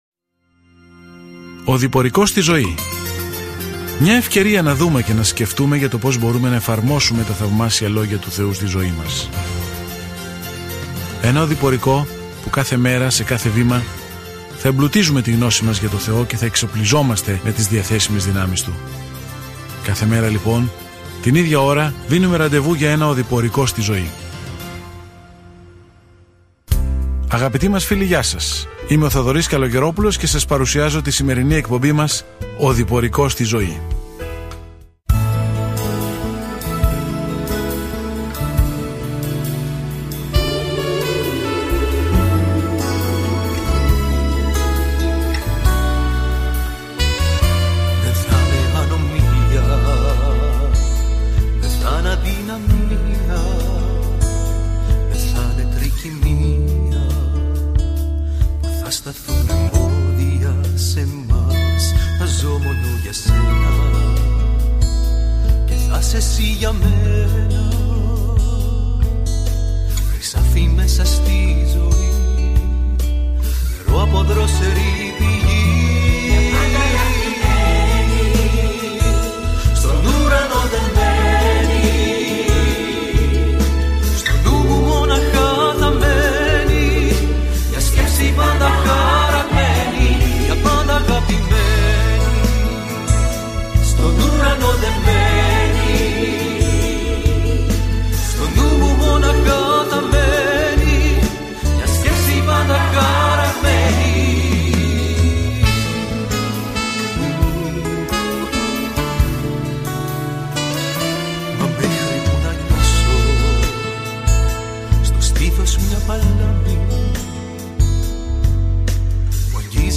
Κείμενο ΙΕΖΕΚΙΗΛ 38:5-23 ΙΕΖΕΚΙΗΛ 39:1-20 Ημέρα 22 Έναρξη αυτού του σχεδίου Ημέρα 24 Σχετικά με αυτό το σχέδιο Ο λαός δεν άκουγε τα προειδοποιητικά λόγια του Ιεζεκιήλ να επιστρέψει στον Θεό, γι' αυτό αντ' αυτού έκανε τις αποκαλυπτικές παραβολές και τρύπησε τις καρδιές των ανθρώπων. Καθημερινά ταξιδεύετε στον Ιεζεκιήλ καθώς ακούτε την ηχητική μελέτη και διαβάζετε επιλεγμένους στίχους από τον λόγο του Θεού.